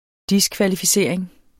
Udtale [ ˈdiskvalifiˌseɐ̯ˀeŋ ]